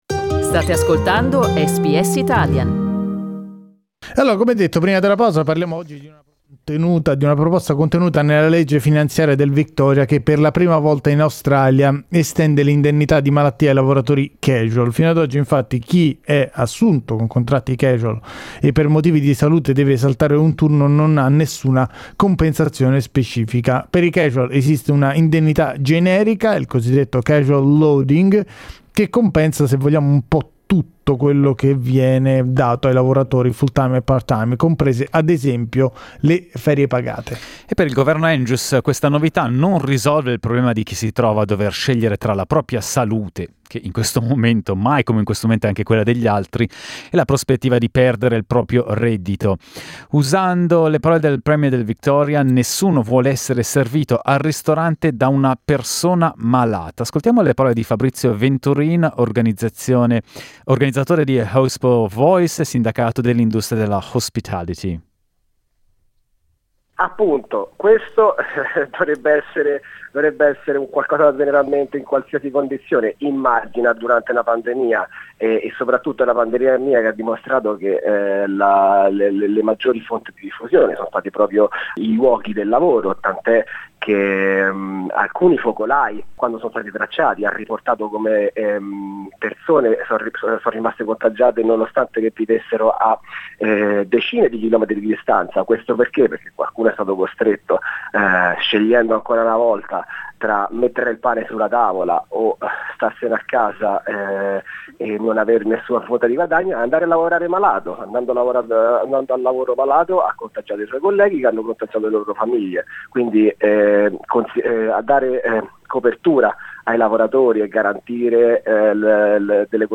Listen to the SBS Italian debate on how this initiative could change the way we work.